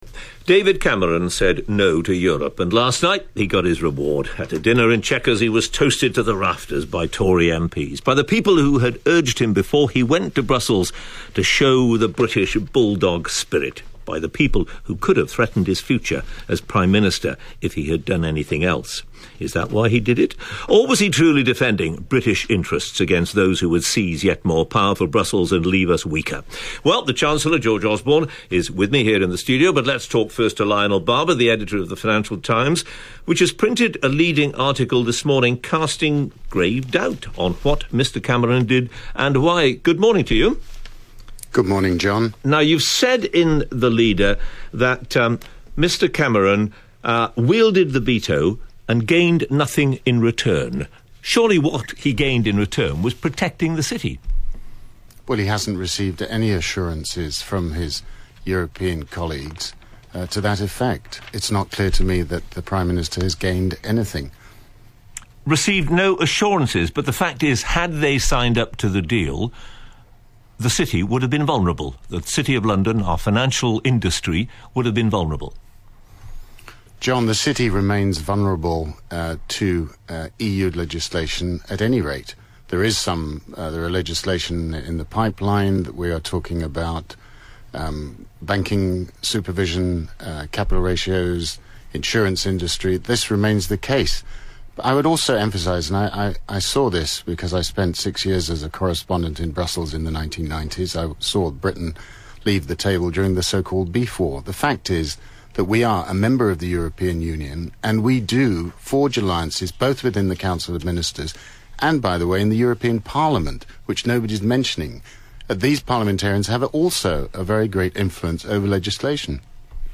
Then on the Today programme, this morning, George Osborne defended Cameron and the decision.
Not according to the Editor of the Financial Times, Lionel Barber, also interviewed by Humphrys immediately prior to Osborne’s interview. Again, I have recorded these two interviews and uploaded here .